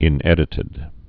(ĭn-ĕdĭ-tĭd)